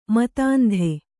♪ matāndhe